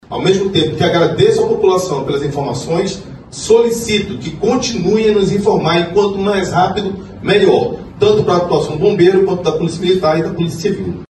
O secretário de Segurança Pública Vinícius Almeida pede apoio da população em realizar denúncias para combater os crimes ambientais: